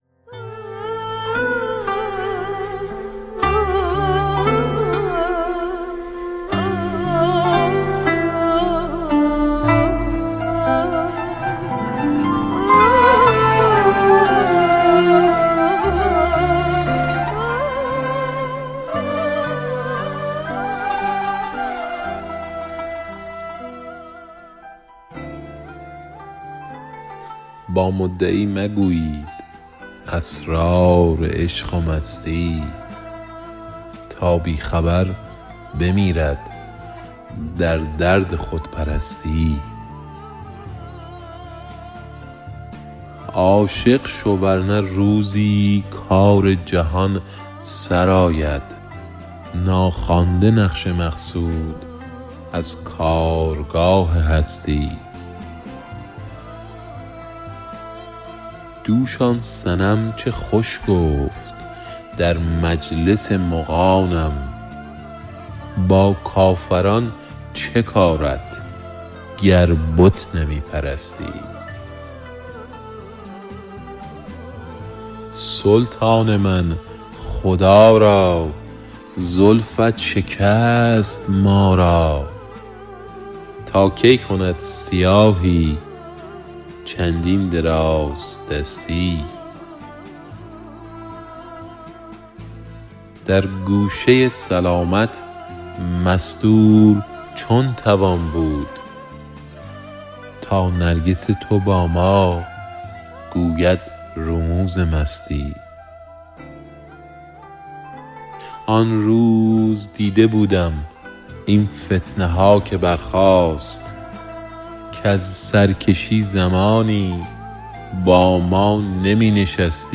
شعر خوانى